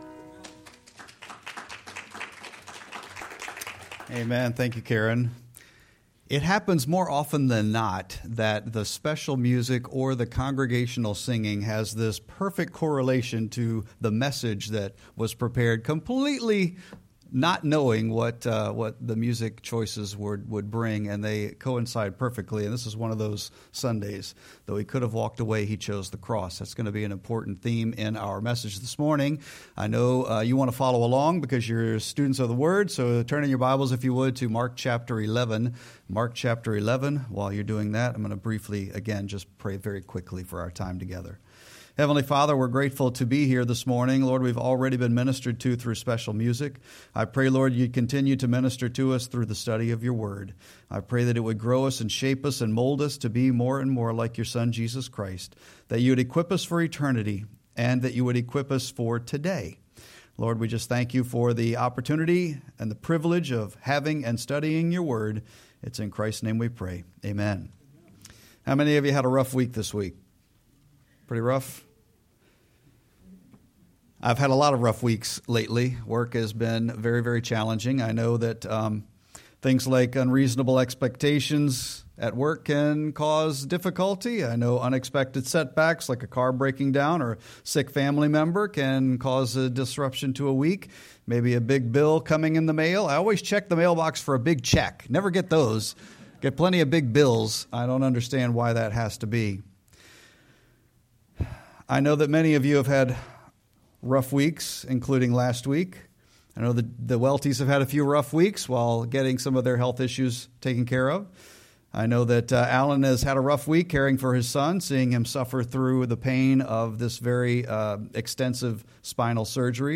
Sermon-4-13-25.mp3